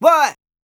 Vox (48).wav